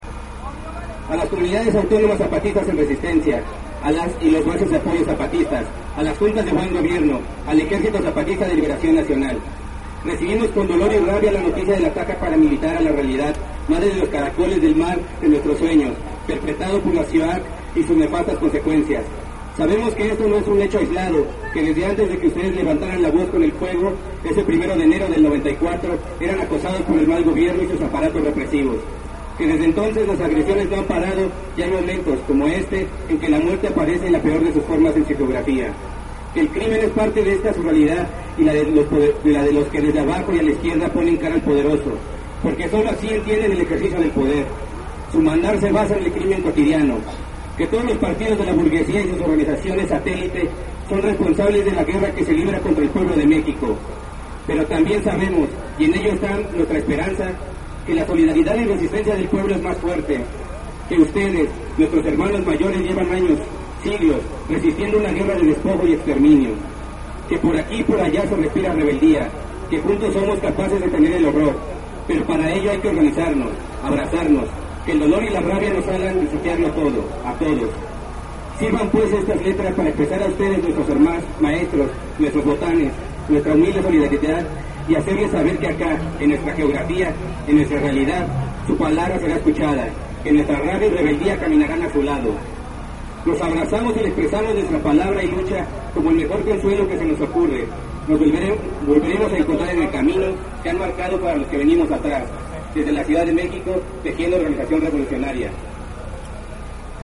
Palabras de TOR